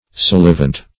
Search Result for " salivant" : The Collaborative International Dictionary of English v.0.48: Salivant \Sal"i*vant\, a. [L. salivans, p. pr. of salivare.